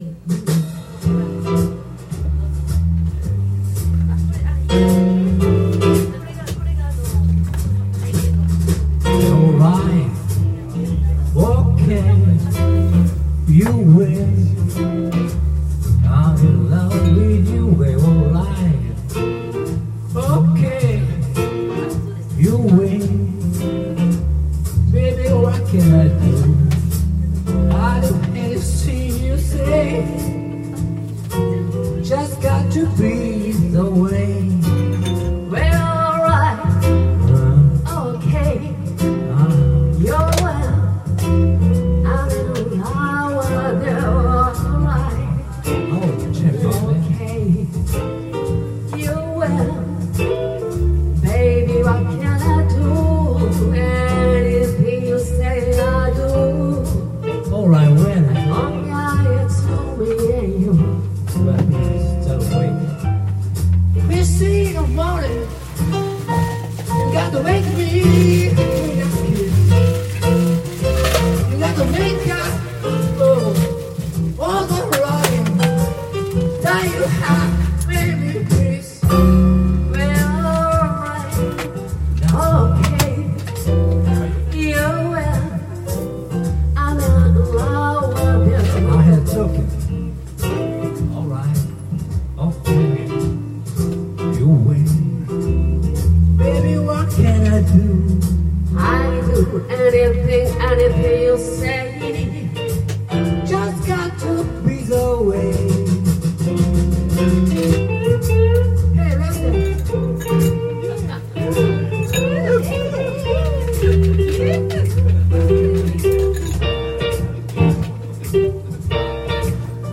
Duet & Chorus Night Vol. 18 TURN TABLE